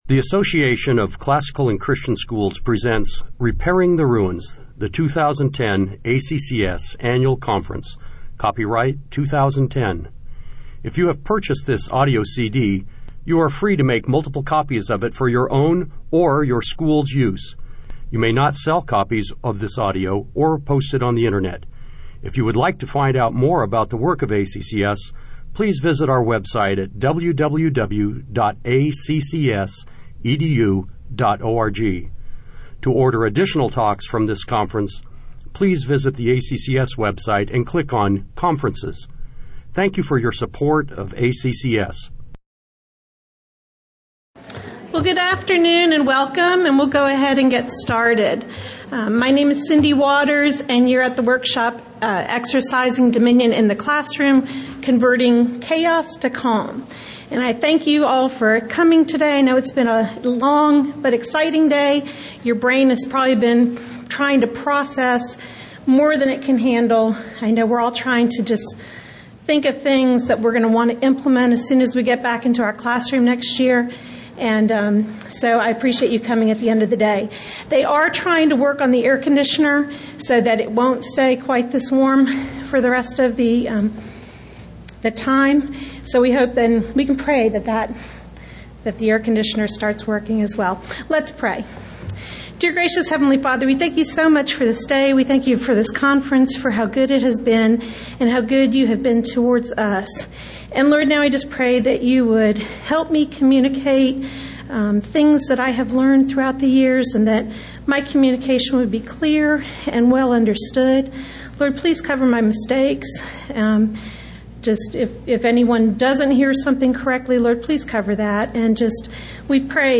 2010 Workshop Talk | 1:02:10 | All Grade Levels, General Classroom, Virtue, Character, Discipline
The Association of Classical & Christian Schools presents Repairing the Ruins, the ACCS annual conference, copyright ACCS.